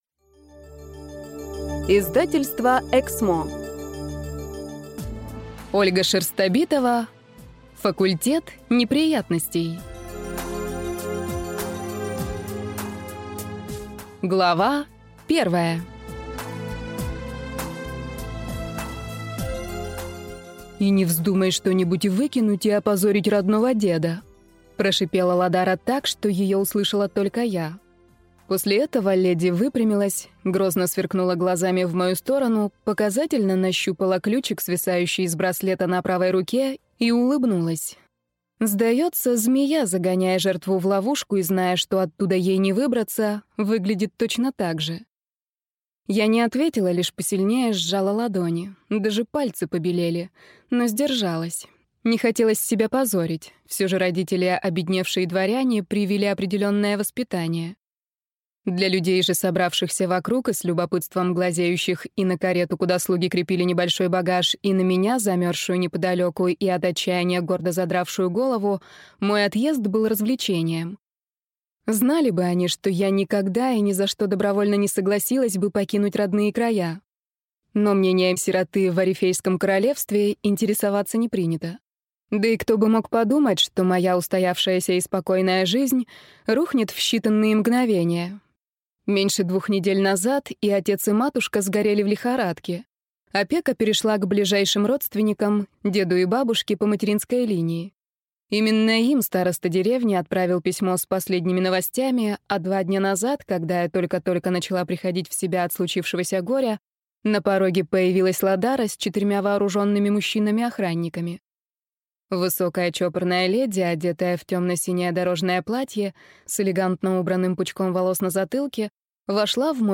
Аудиокнига Факультет неприятностей | Библиотека аудиокниг